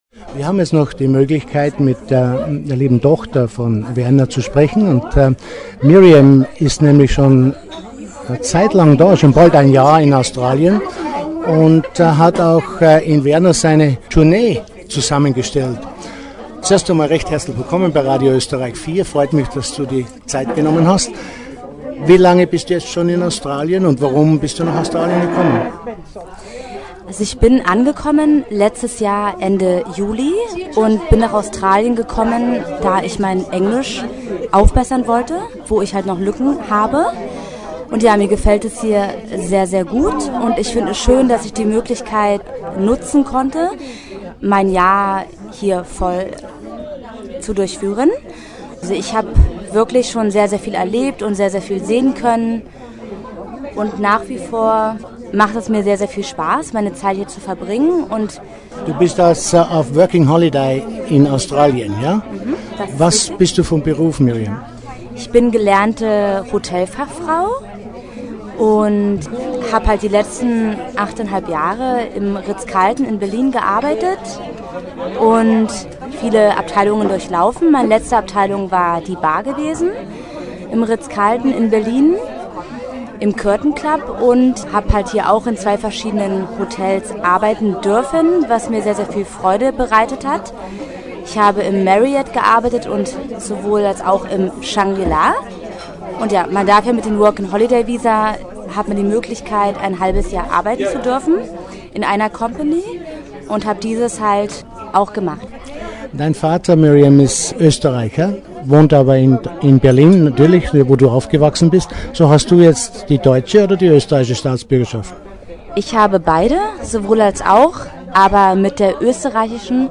RA4 Interview